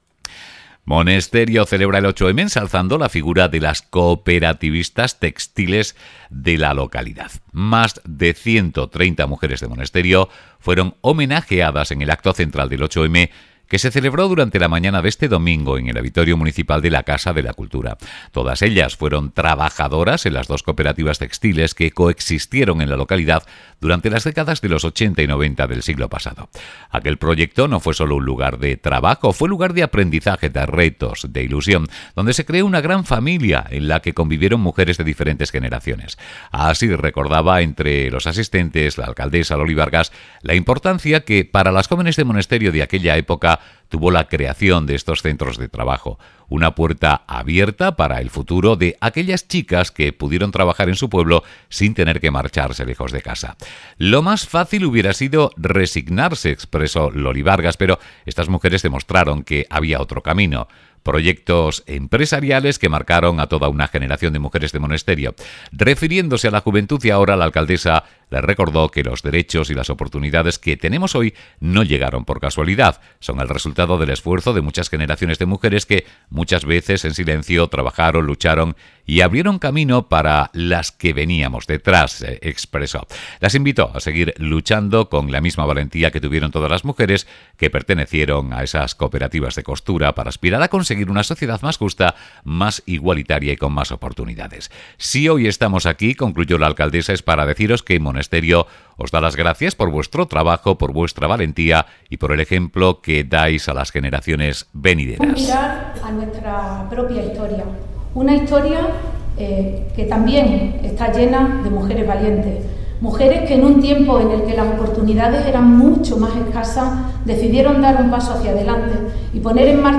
6cxac8MACTOINSTITUCIONALMONESTERIO.mp3